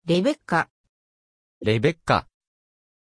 Pronunția numelui Rebecka
pronunciation-rebecka-ja.mp3